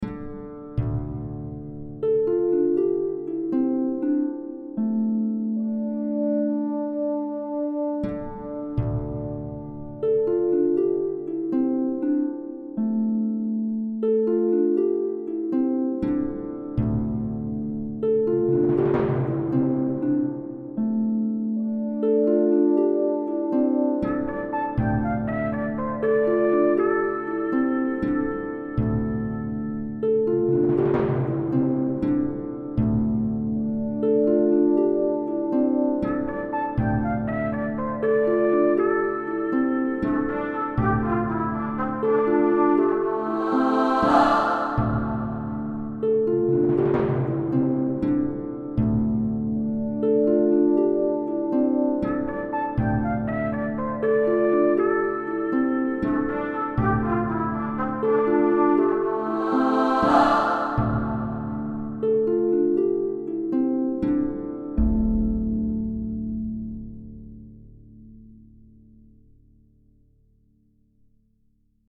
ambient
A short ambient tune for an enchantmented lake.